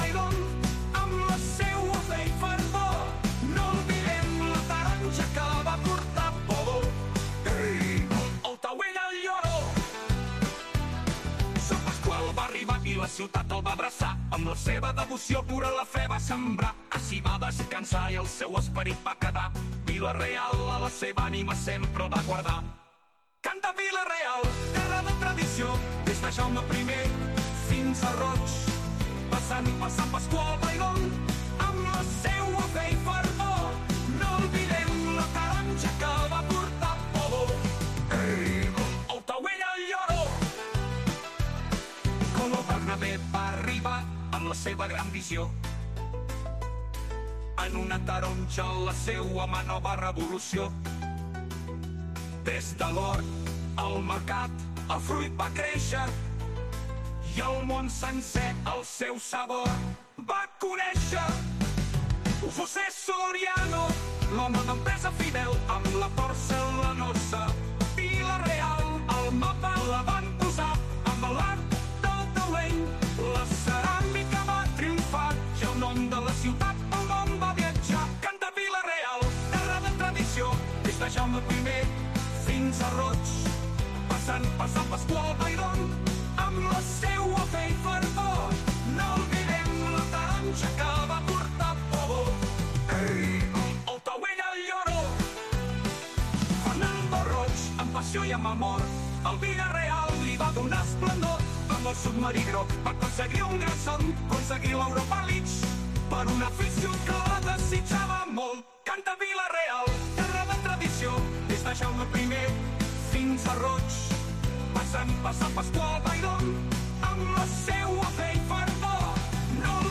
Hui 20 de febrer de 2025, parlem amb l´alcalde de Vila-real, José Benlloch
20-02-25-Jose-Benlloch-alcalde-vila-real.mp3